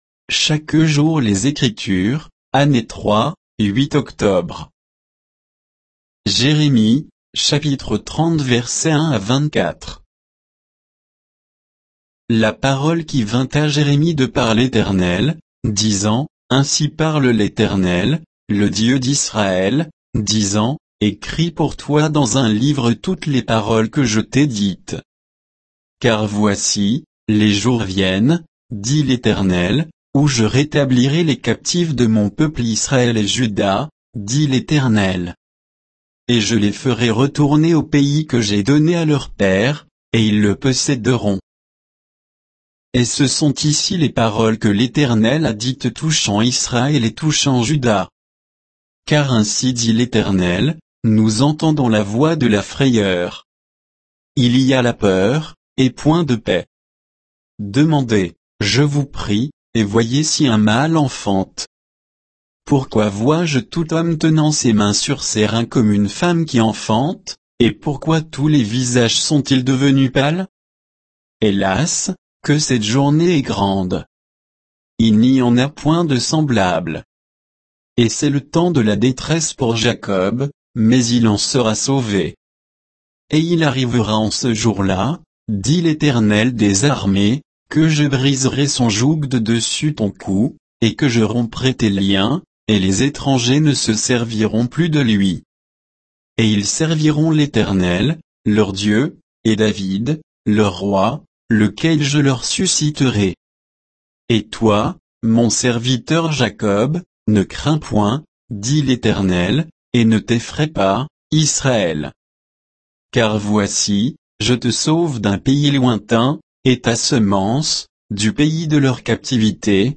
Méditation quoditienne de Chaque jour les Écritures sur Jérémie 30